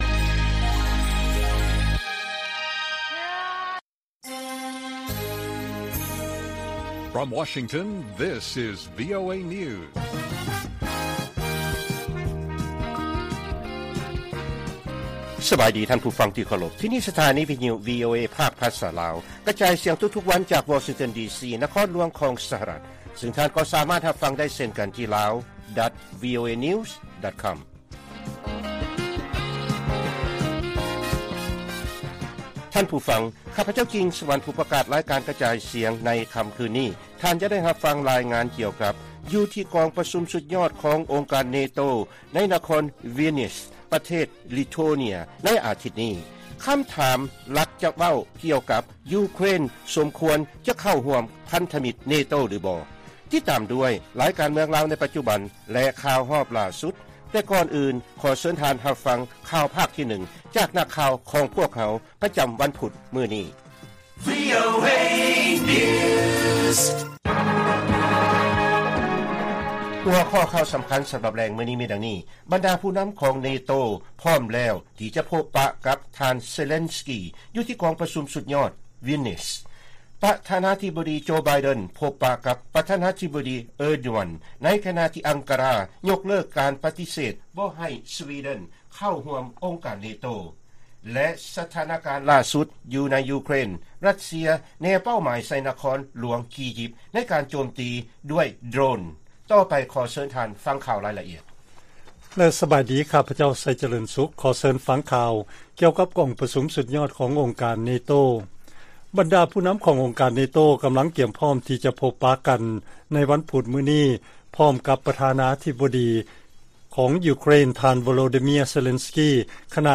ລາຍການກະຈາຍສຽງຂອງວີໂອເອ ລາວ: ບັນດາຜູ້ນຳຂອງ ເນໂຕ ພ້ອມແລ້ວທີ່ຈະພົບປະກັບ ທ່ານເຊເລັນສກີ ຢູ່ທີ່ກອງປະຊຸມສຸດຍອດ ວີລນຽສ